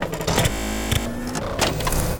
combat
attack1.wav